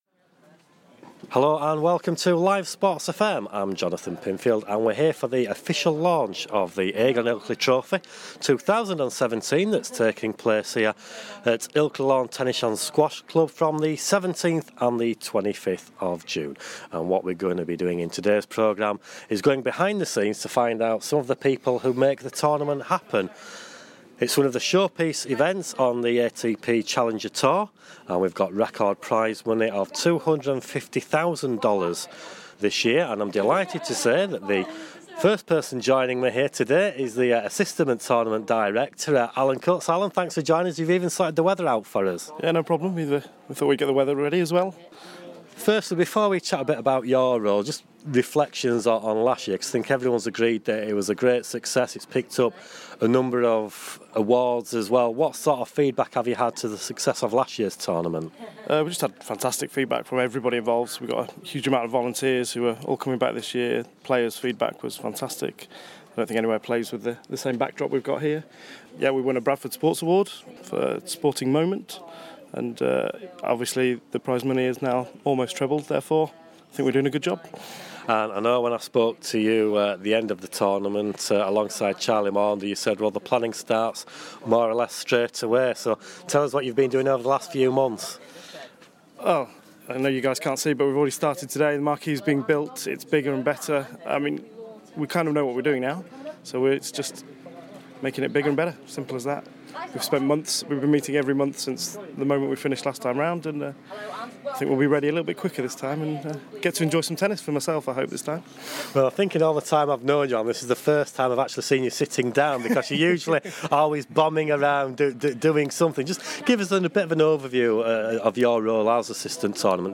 takes a guided tour of Ilkley Tennis Club ahead of the Aegon Ilkley Trophy 2017